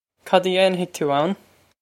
Pronunciation for how to say
Cod ah yain-hig too ow-n?
This is an approximate phonetic pronunciation of the phrase.